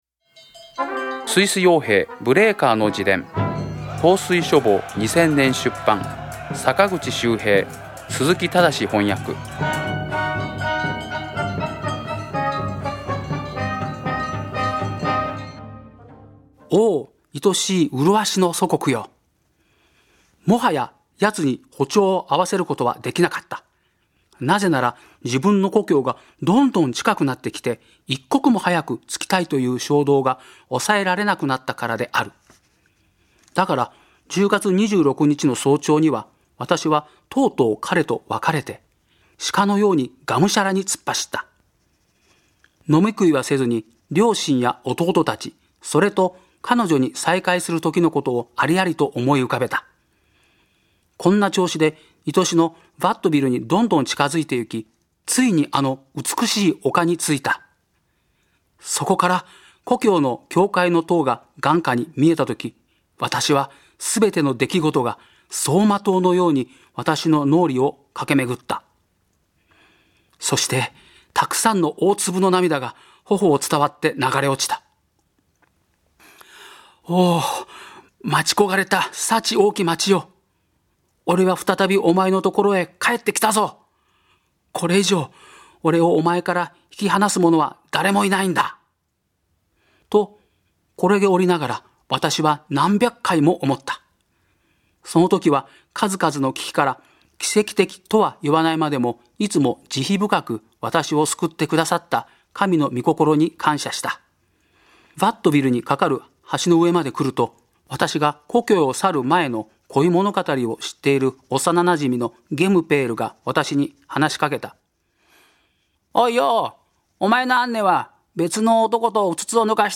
朗読『スイス傭兵ブレーカの自伝』最終回